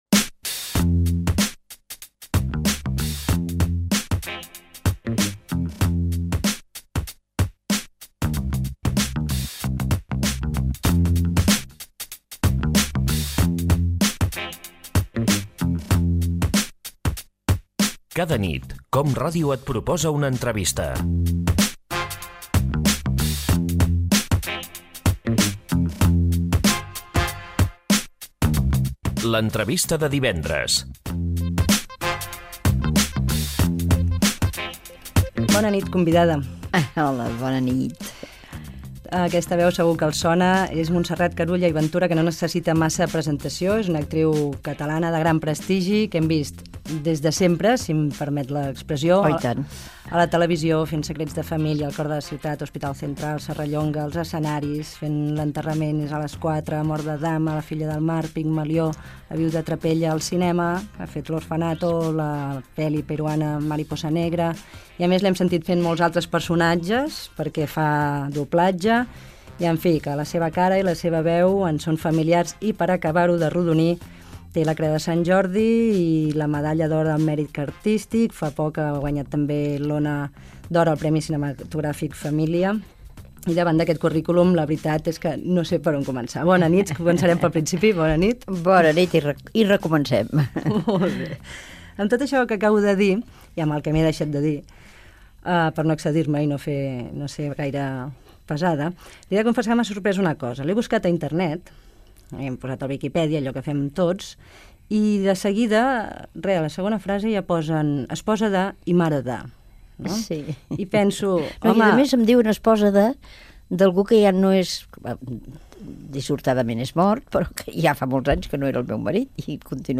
Careta, presentació i entrevista a l'actriu Montserrat Carulla, qui parla de la seva família i de l'activitat professional